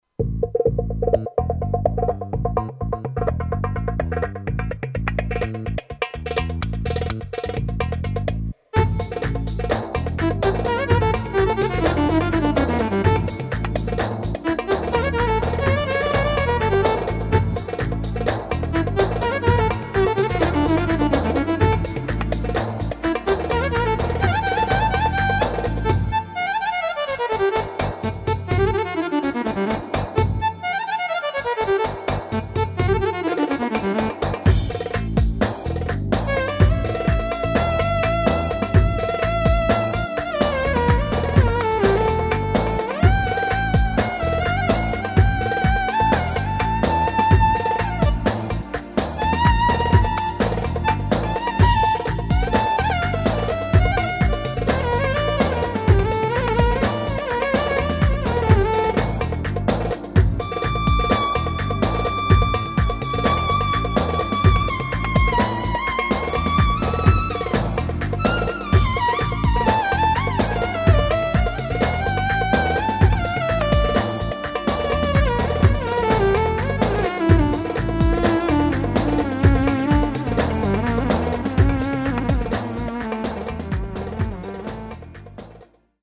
Yamaha Psr or700tr v1 9-8 Romdi :D
Yaylı Grubu ve Keman Sesiyle Ufak Bir 9-8 Çalışması Yine Bizlerle :D Çalmaya çalıştım artık ne kadar olduysa :D :D Dip Not : Katkılarından dolayı ev...